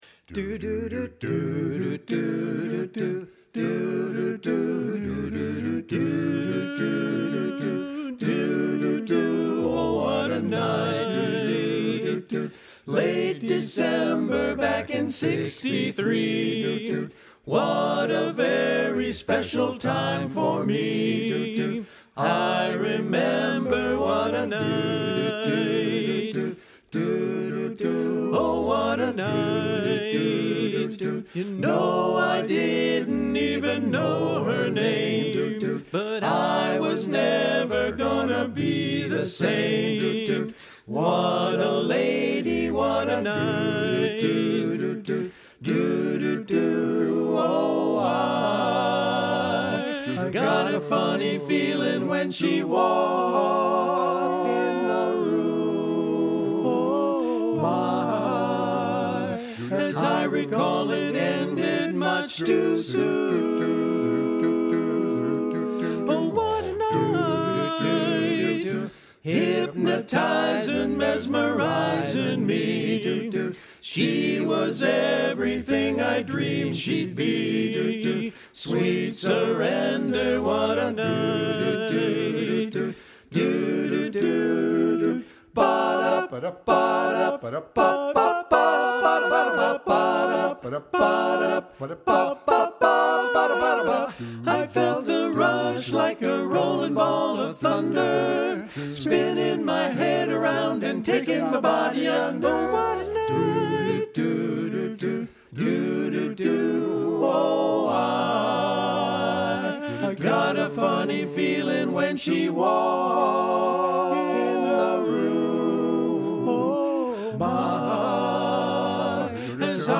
Download Happy Days intro full mix SLOWER